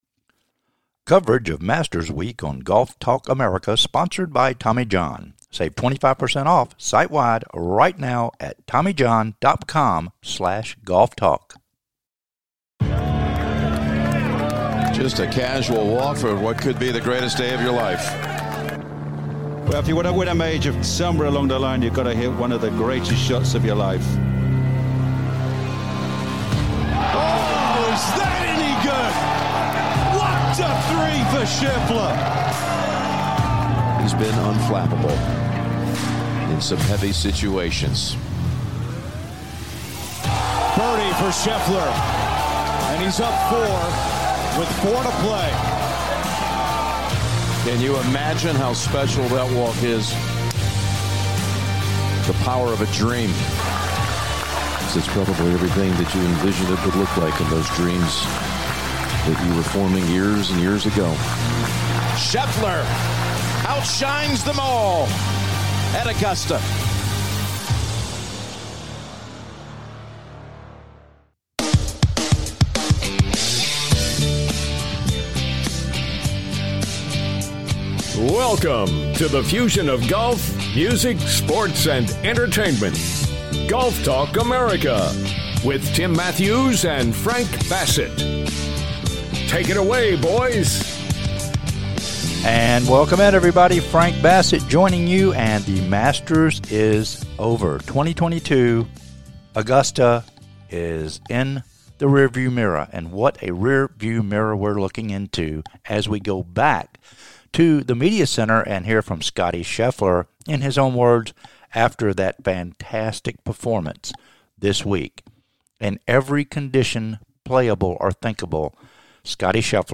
The Full Interview "LIVE" from The Media Center at Augusta National Golf Club With Scottie Scheffler